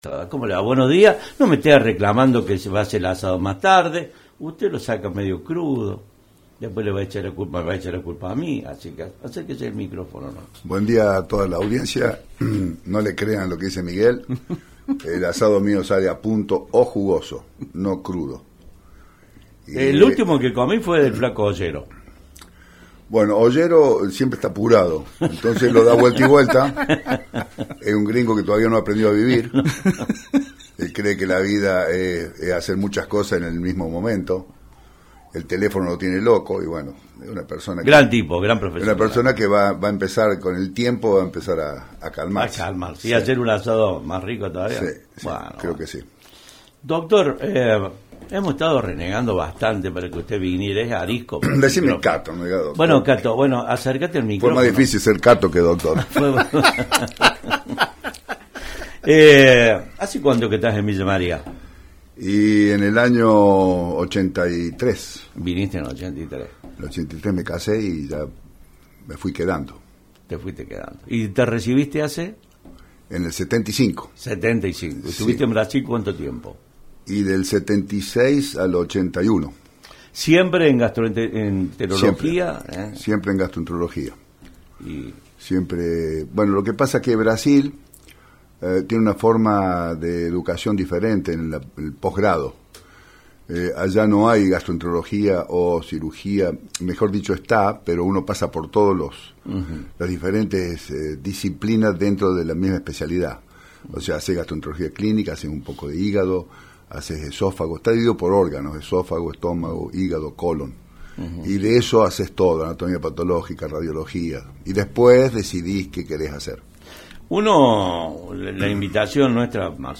Un padecimiento que tomado a tiempo es altamente curable. Los mensajes y participación telefónica de la audiencia dieron cuenta de la importancia y atención que tiene la ciudadanía sobre la citada problemática.